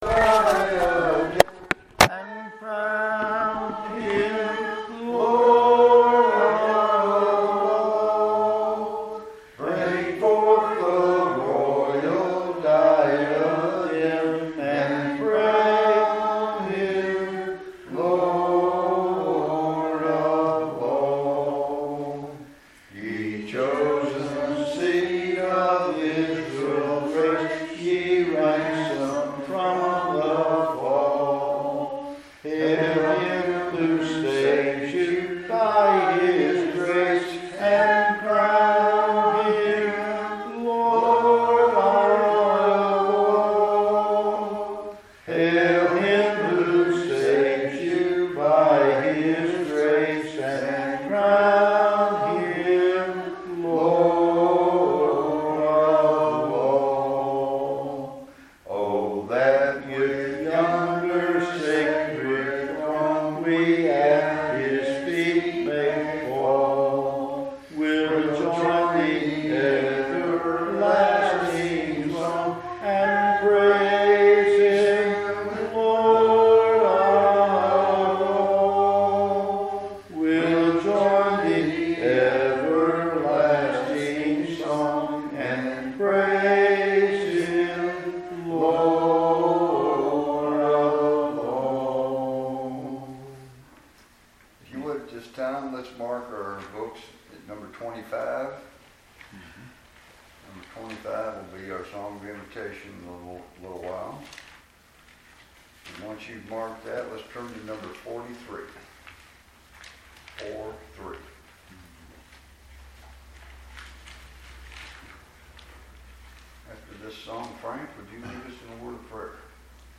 Night service | Crockett Church of Christ